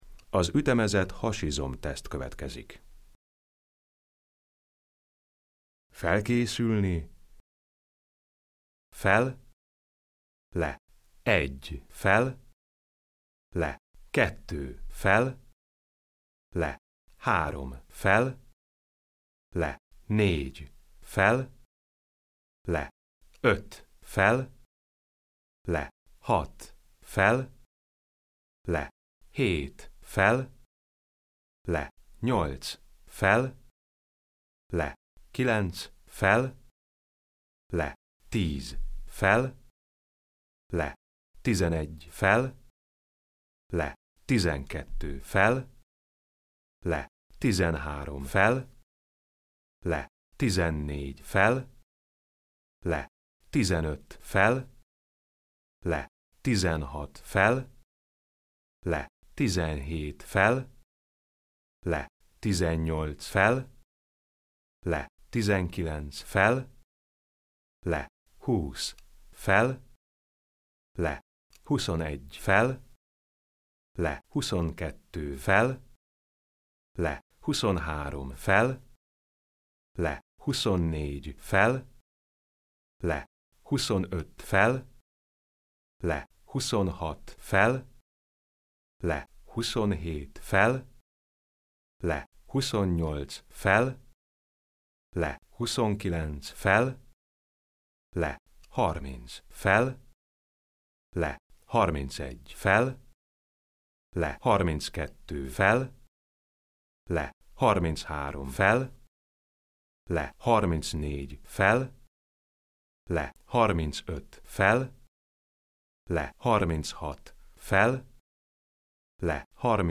Ütemezett hasizom teszt: